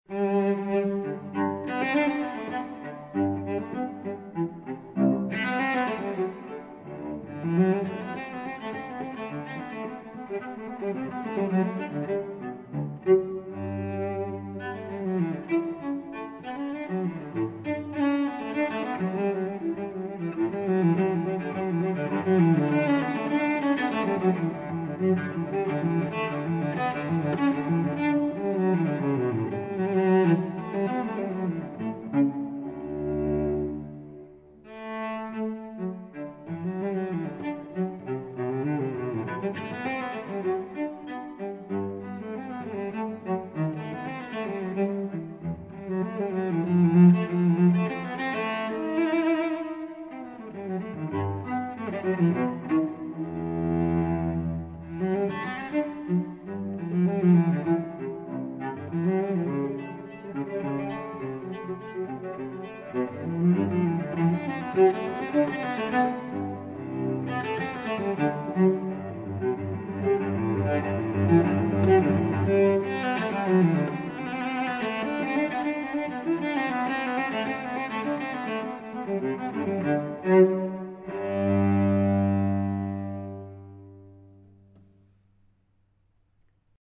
Las Suites para Violonchelo de Juan Sebastián Bach
Suite Nr. I en sol mayor para Violonchelo - BWV 1007